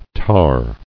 [tahr]